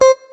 note_beepy_8.ogg